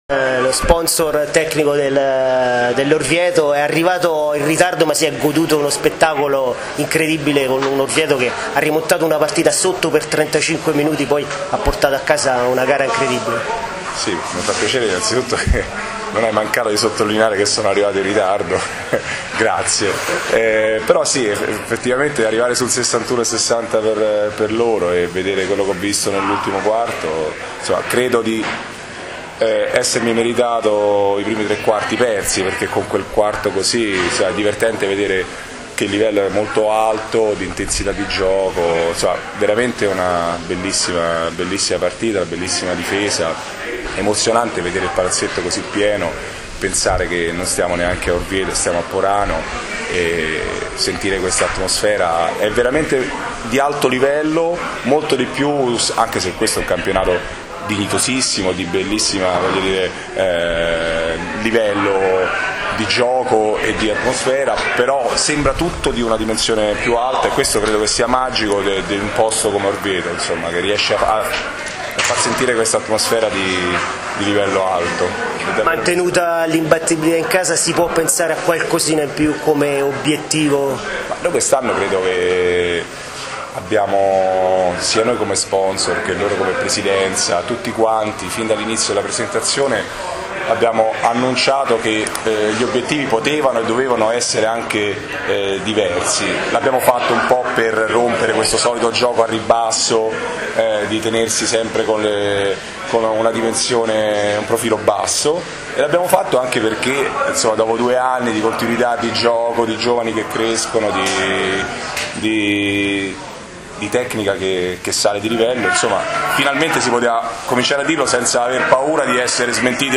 INTERVISTE del DOPO GARA